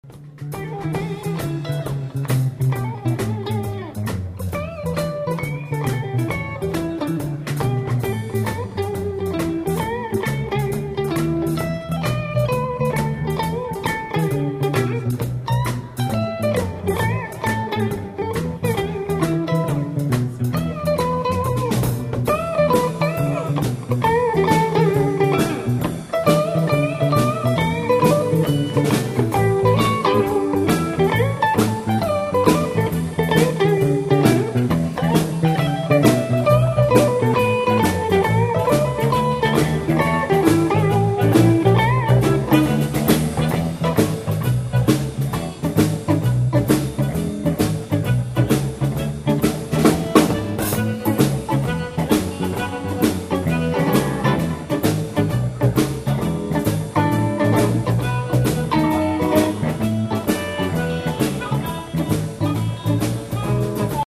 harmonicas
Des extraits, (30 secondes environ) du concert enregistré le 3 Mars 2000
au Relais de la Reine Margot (Longvic, Côte d'or) :